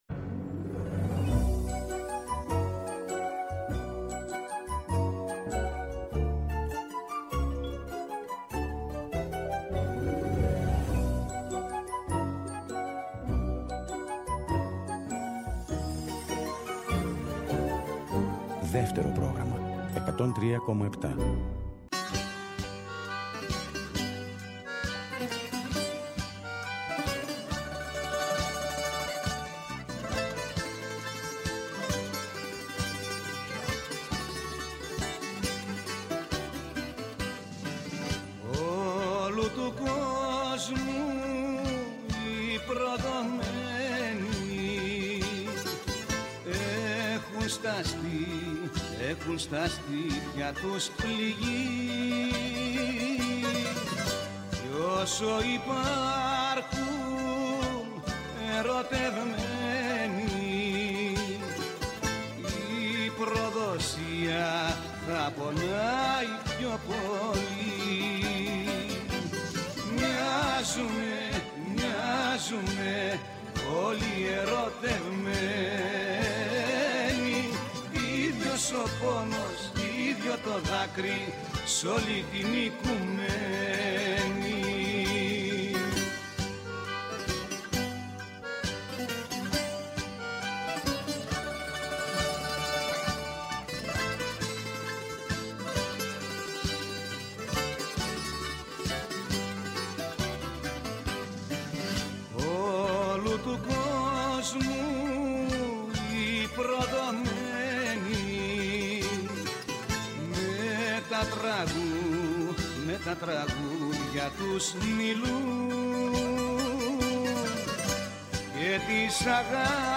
Για τη ζωή του και το έργο του μας μιλούν ο Κώστας Φασουλάς και ο Χρήστος Νικολόπουλος.